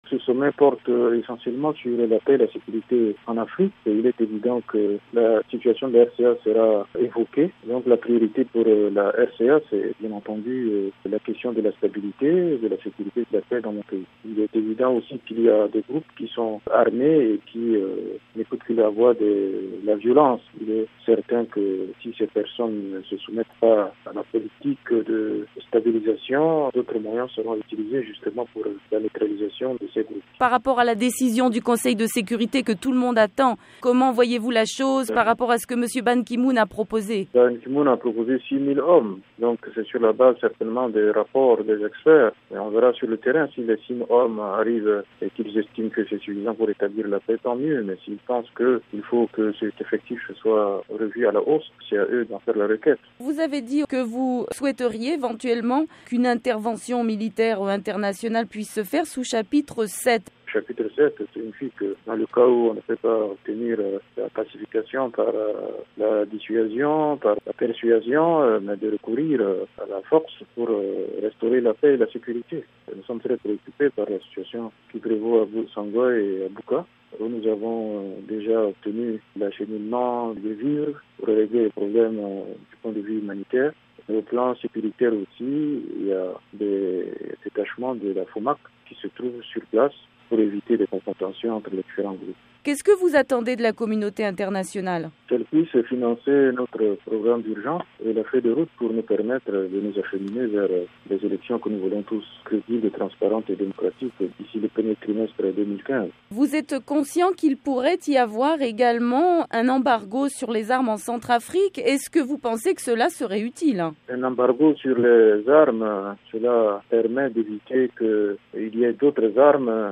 La VOA a joint le Premier ministre centrafricain à Bangui, à la veille de son départ pour le sommet de Paris.